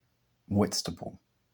Captions English Whitstable Pronunciation